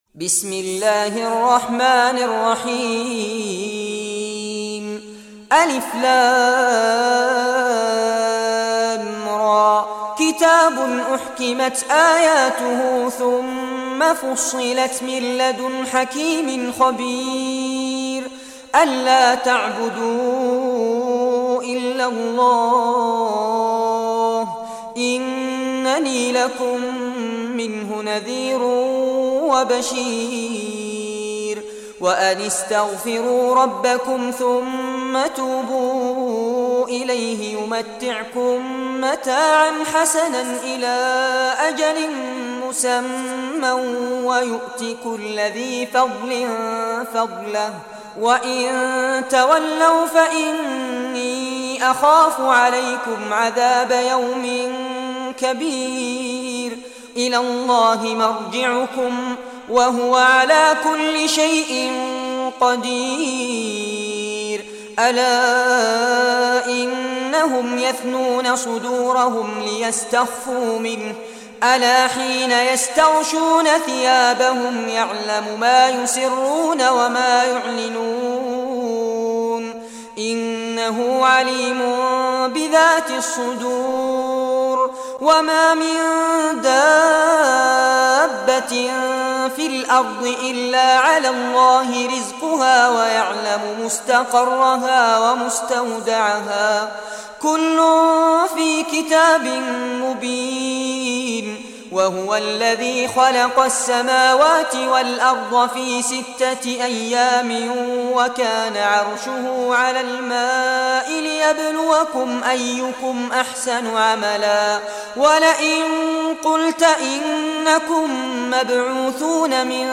Surah Hud, listen or play online mp3 tilawat / recitation in Arabic in the beautiful voice of Sheikh Fares Abbad.
11-surah-hud.mp3